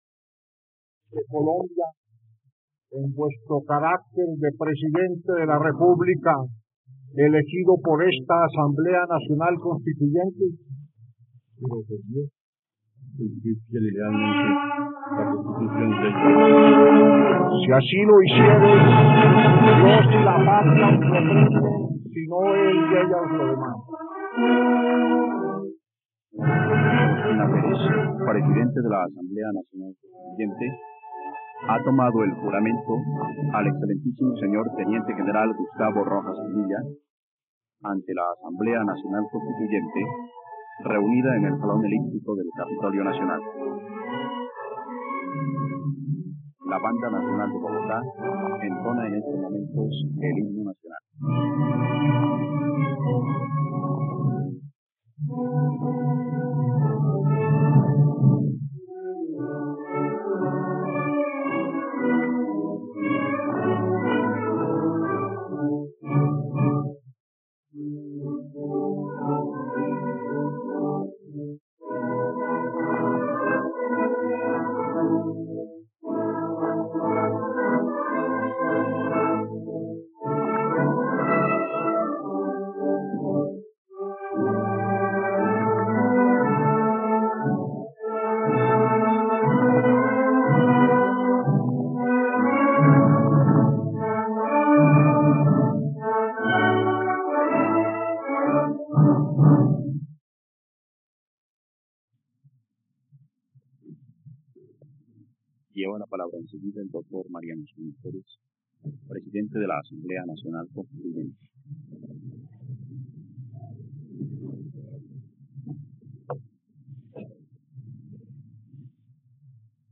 Discurso de Ospina Pérez en la posesión de Gustavo Rojas Pinilla
..Escucha ahora el discurso de Mariano Ospina Pérez en la posesión de Gustavo Rojas Pinilla, el 7 de agosto de 1954, en la plataforma de streaming RTVCPlay.